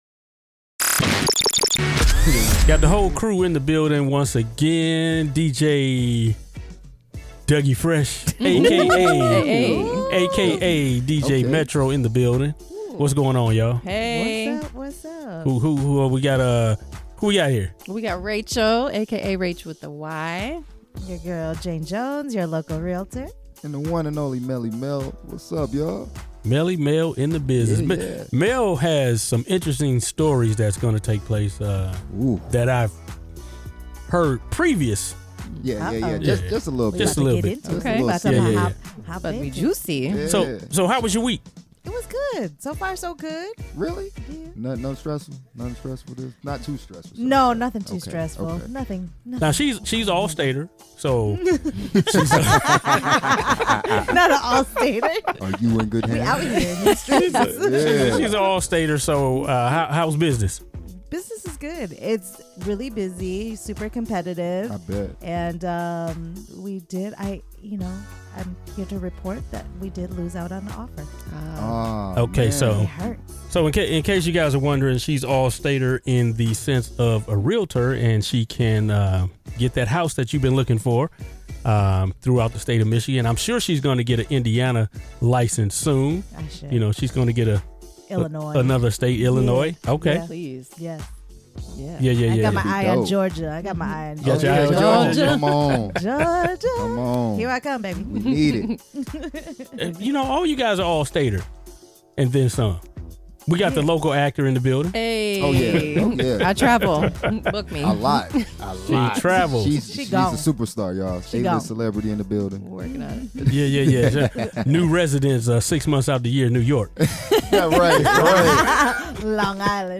Looking for a radio show that’s truly one of a kind?
With a mix of drama, comedy, and engaging conversation, there’s never a dull moment when we’re on the air. Don’t miss out on the fun–be sure to tune in every Saturday from 5-7 pm on WVBH Power 105.3 in Benton Harbor.